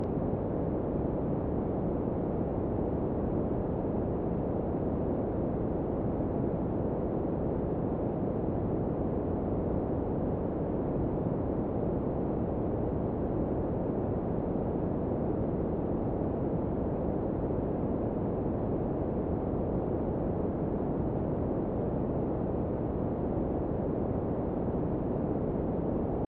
电动车超速报警音
描述：新日电动车防盗器超速报警提示音，使用手机录制
Tag: 超速报警 新日电动车 电动车 新日 超速